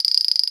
PERC - GLITCH.wav